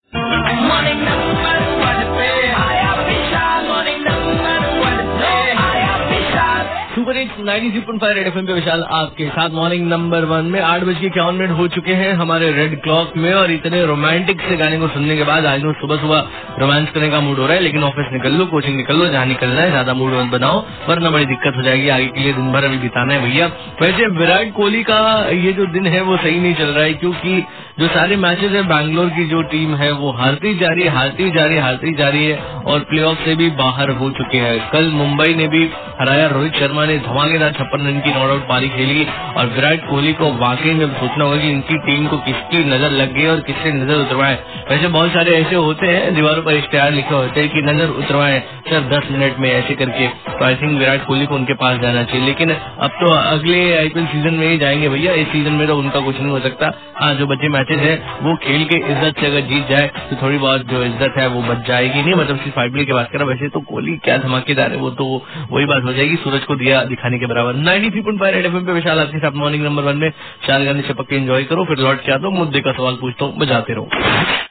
RJ ABOUT VIRAT KOHALI